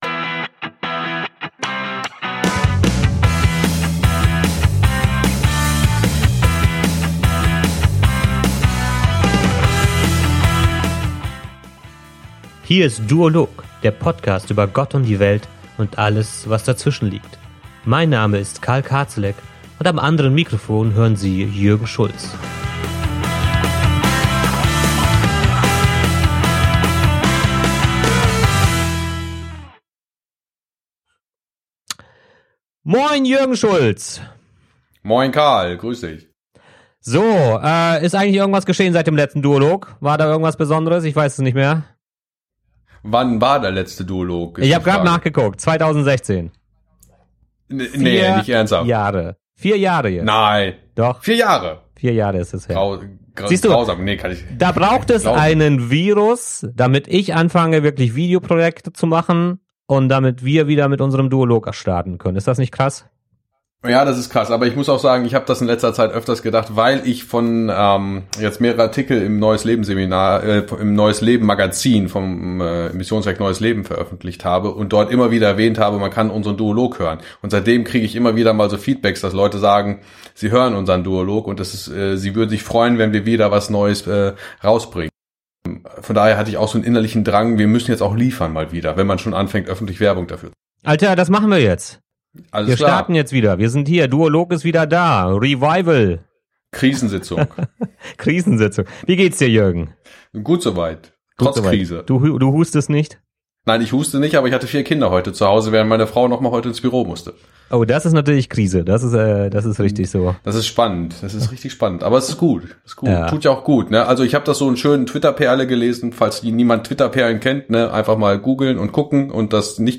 Zwei Pastoren haben in der Krise keinen Plan, aber davon ganz viel. Die Corona-Epidemie schmeißt viel gewohntes in unserem Alltag über den Haufen.